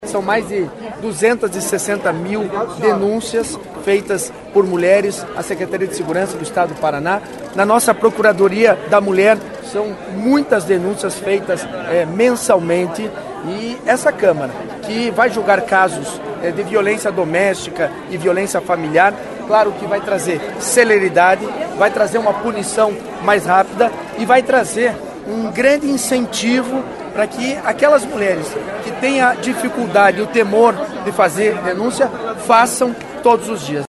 O presidente da Assembleia, Alexandre Curi (PSD), falou sobre o projeto.